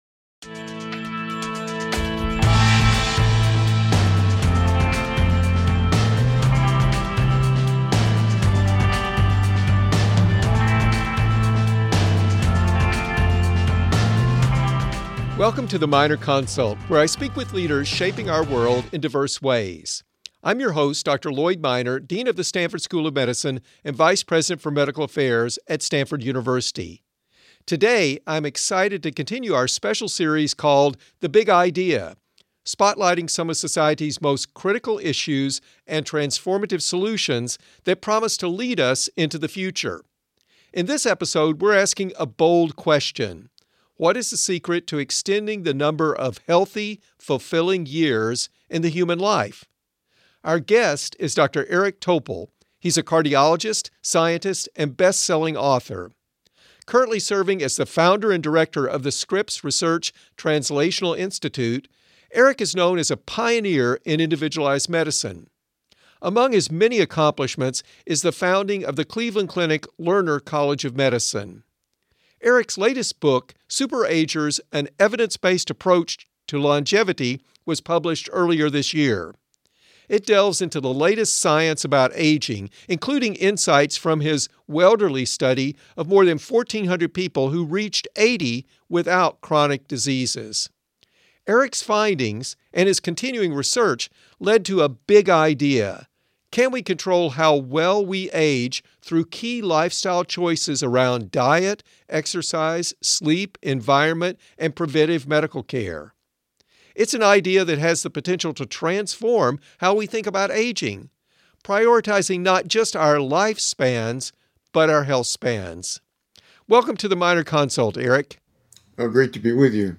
for a conversation about why immune health may be more important than genetics in promoting healthy aging.